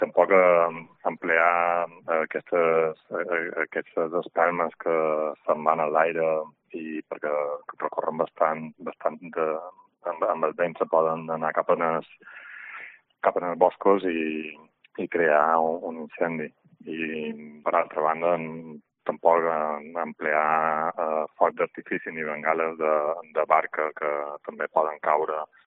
CORTE DE VOZ BOMBEROS